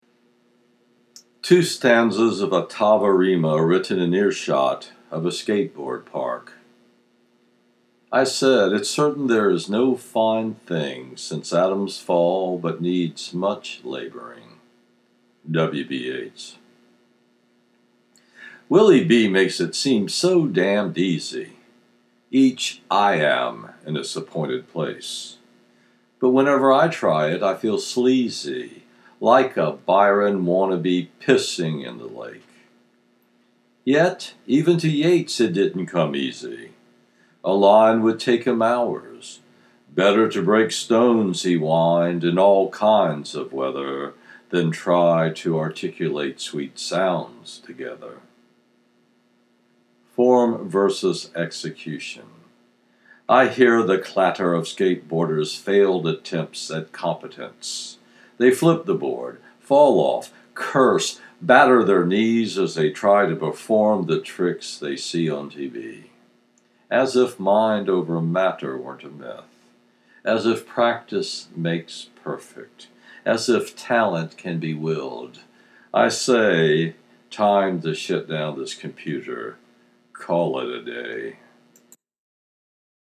Skateboard Wipeout